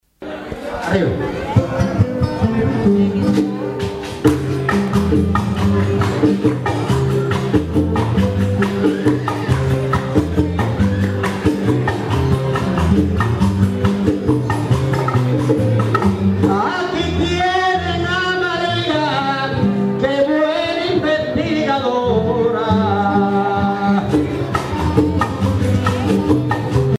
Pièces musicales tirées de la Parranda Tipica Espirituana, Sancti Spiritus, Cuba